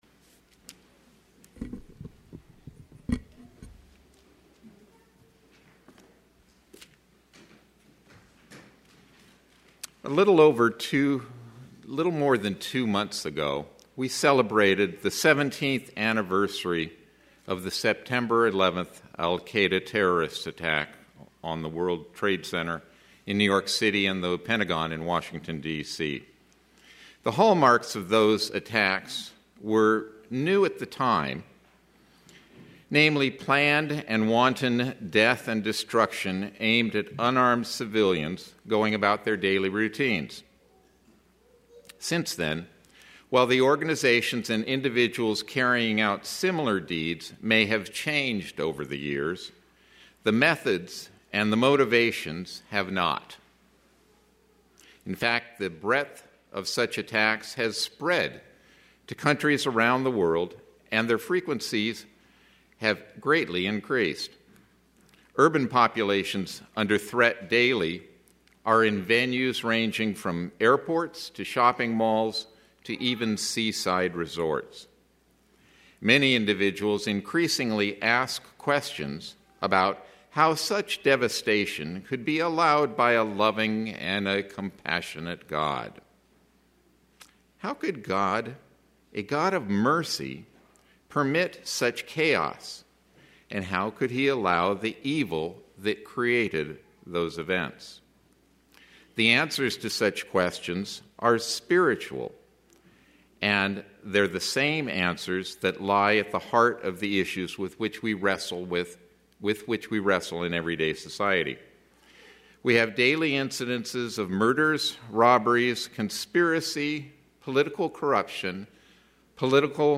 Split-Sermon
Given in Phoenix East, AZ